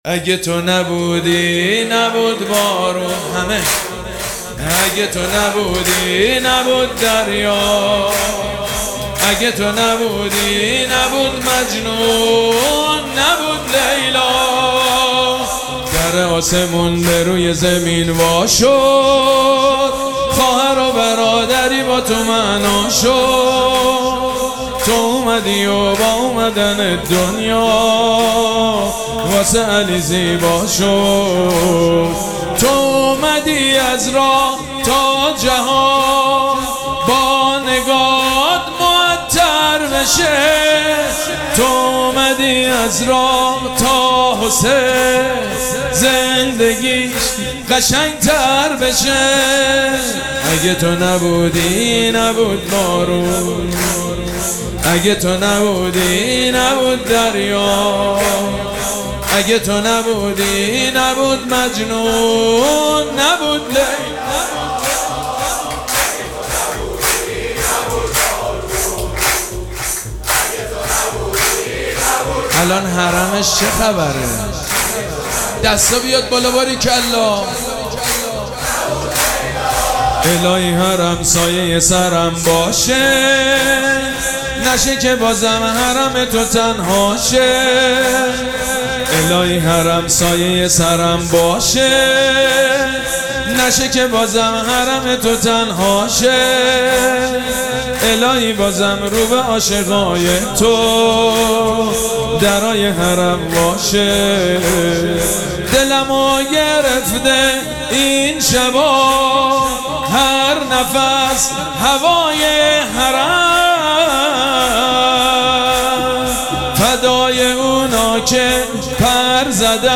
مراسم جشن ولادت حضرت زینب سلام‌الله‌علیها
سرود
حاج سید مجید بنی فاطمه